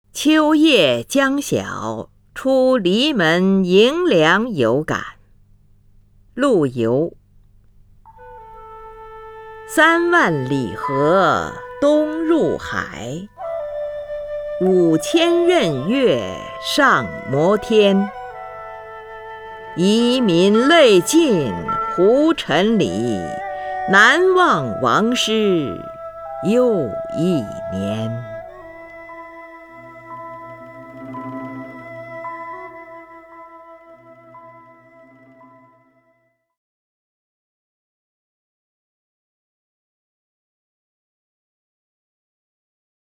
林如朗诵：《秋夜将晓出篱门迎凉有感》(（南宋）陆游)
名家朗诵欣赏 林如 目录